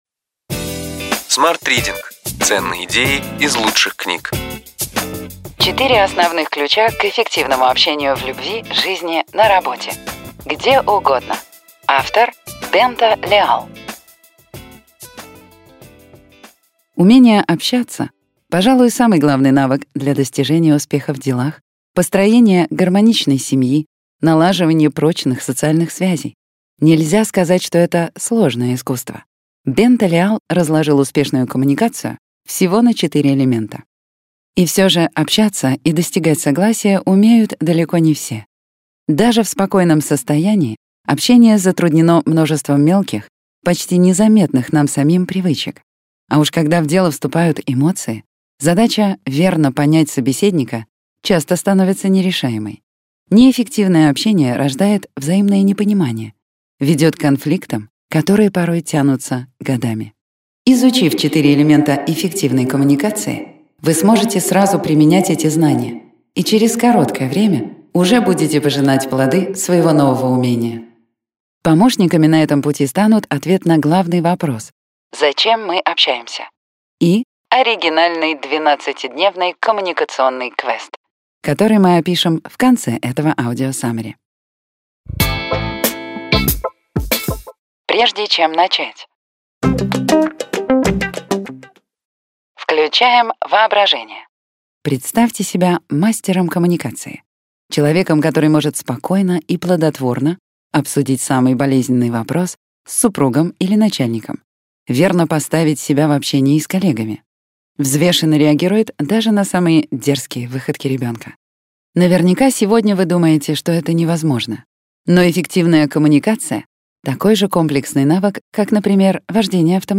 Аудиокнига Ключевые идеи книги: 4 основных ключа к эффективному общению в любви, жизни, на работе – где угодно!